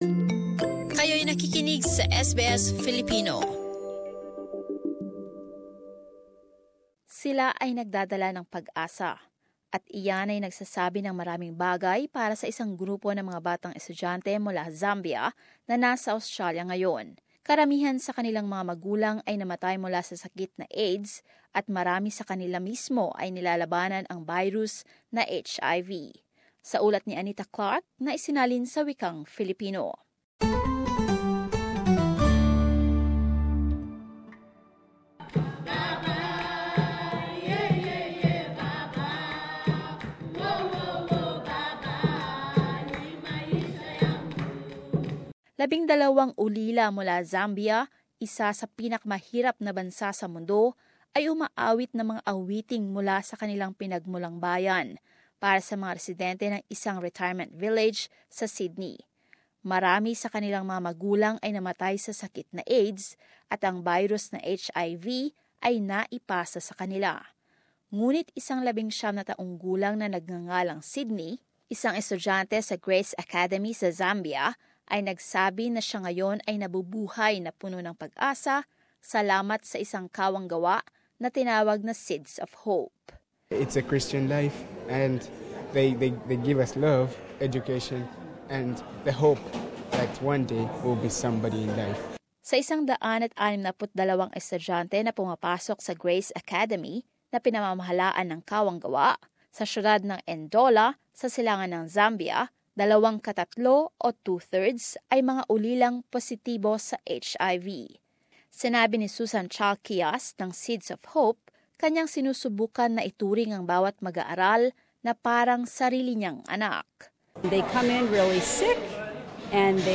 Students from Zambia's Grace Academy sing at a Sydney retirement village Source: SBS